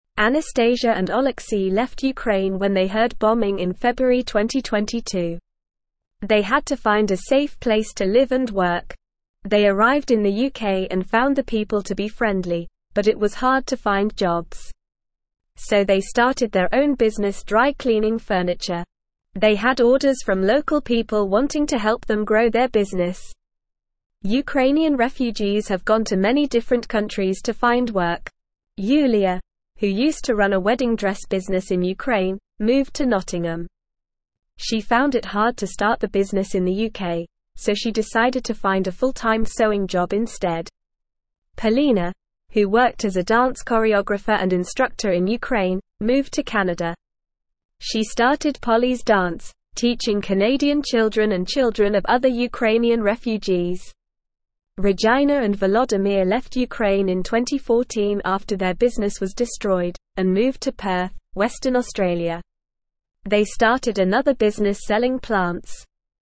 Normal
English-Newsroom-Beginner-NORMAL-Reading-Refugees-Start-Own-Businesses-in-New-Countries.mp3